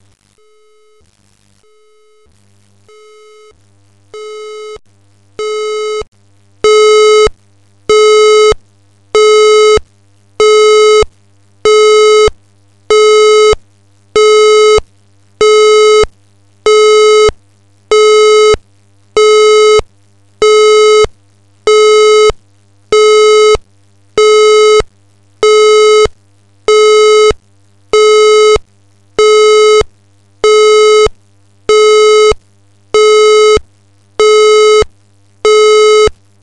Announcement Tones
“Alert sound” this is as per the OLD AS2220 tones however it is allowed for use in a new 1670.4 system if staged evacuation is required.